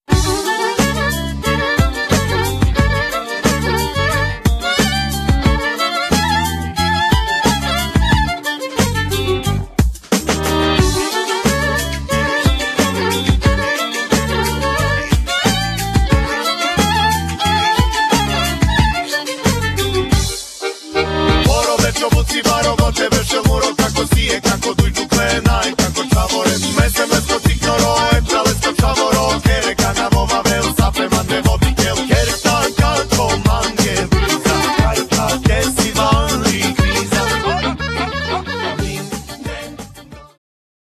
gitara guitar, śpiew vocals
skrzypce violin
akordeon accordion
gitara basowa bass guitar
perkusja drums
instr. perkusyjne percussions